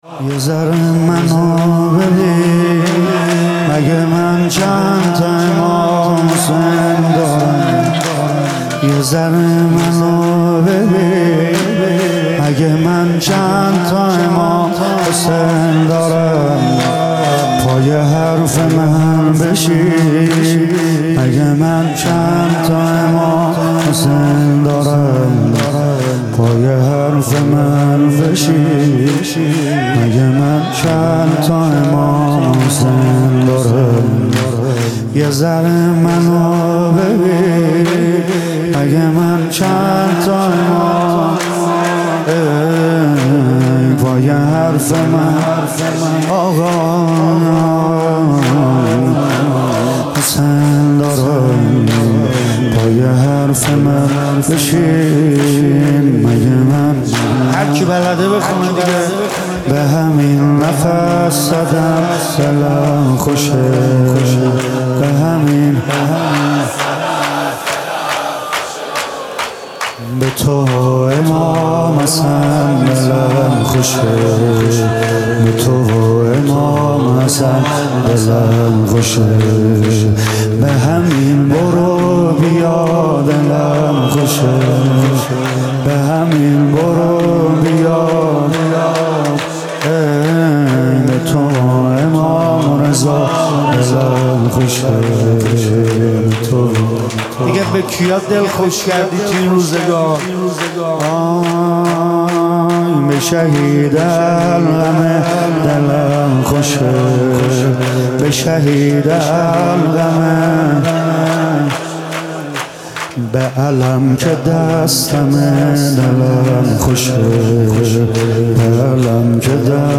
music-icon شور: شهدا مارو به اون خلوتتون راهی بدید